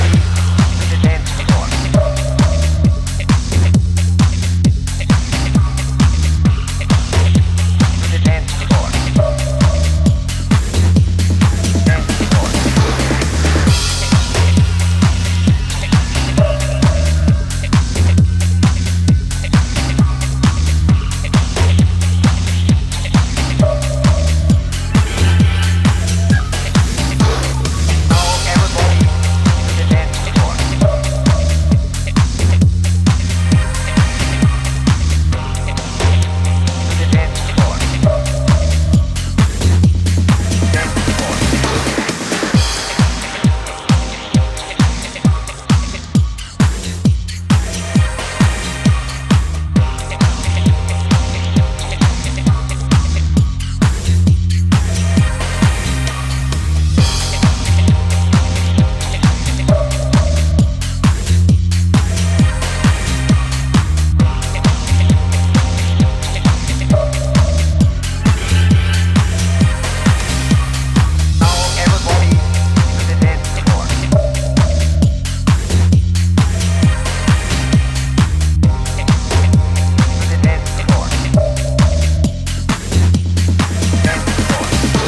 a sublime and spaced-out club sound